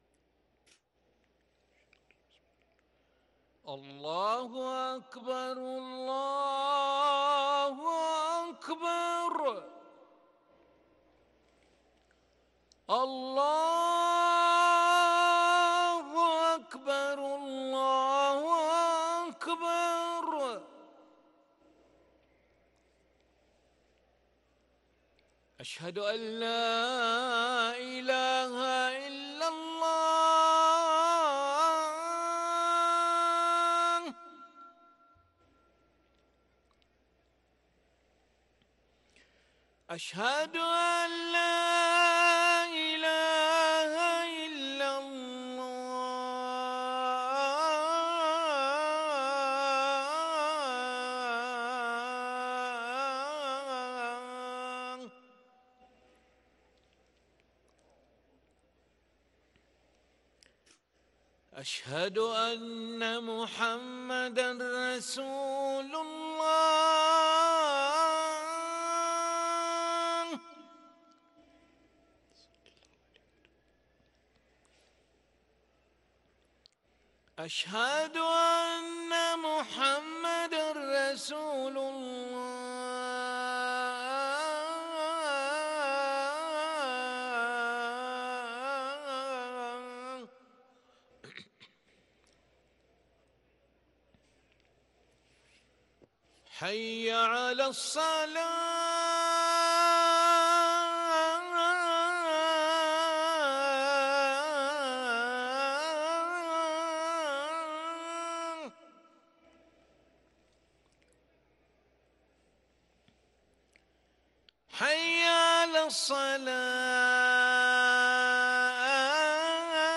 أذان العشاء للمؤذن علي ملا الأحد 10 جمادى الأولى 1444هـ > ١٤٤٤ 🕋 > ركن الأذان 🕋 > المزيد - تلاوات الحرمين